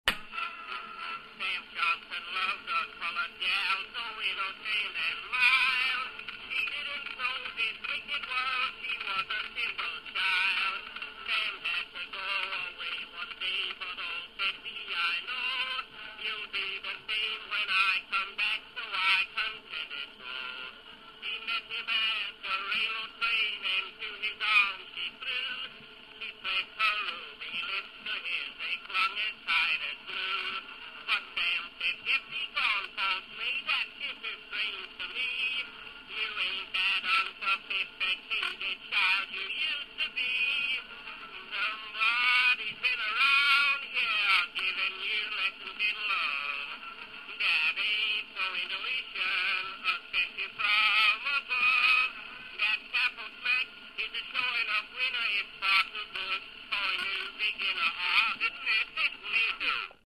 Пластинка старого граммофона играет